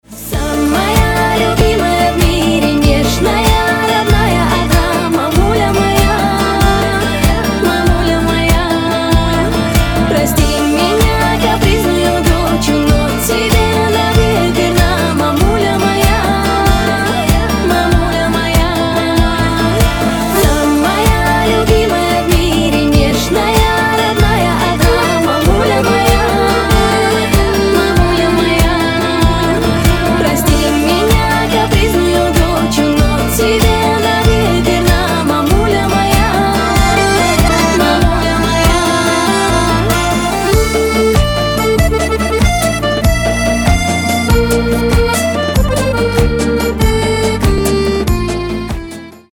Рингтоны шансон